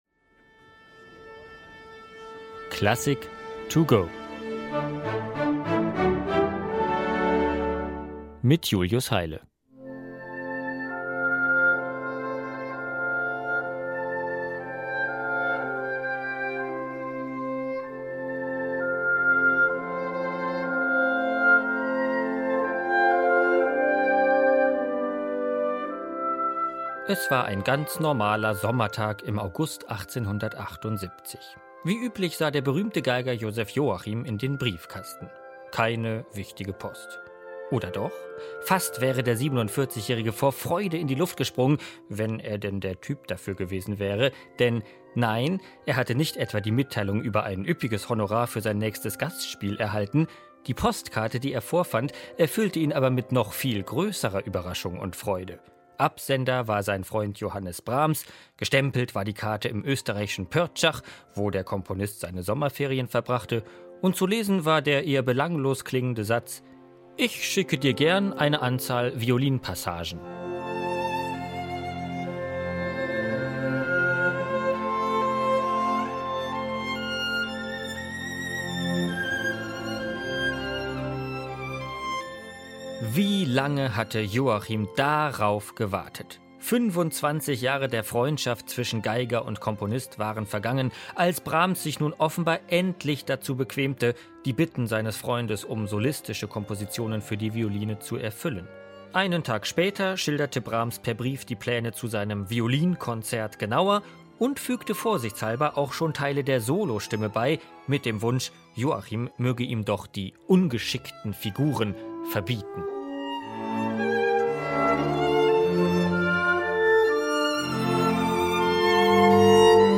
Konzerteinführung.